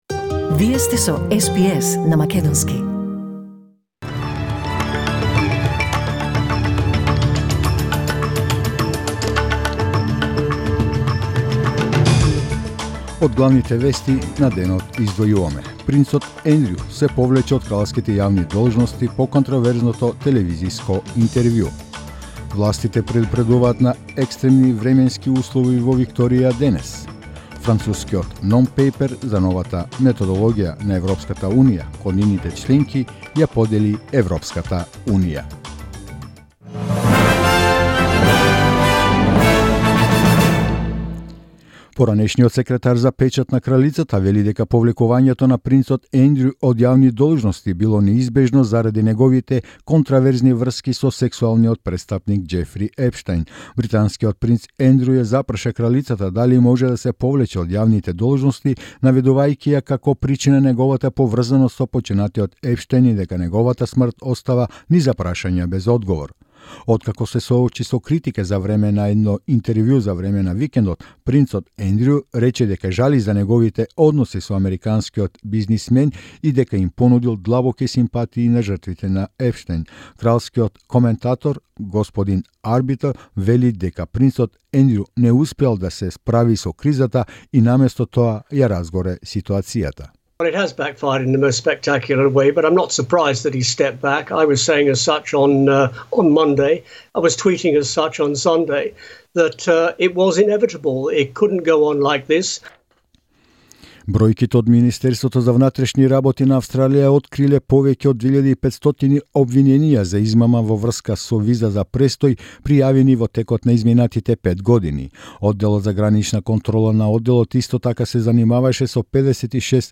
SBS News in Macedonian 21st November 2019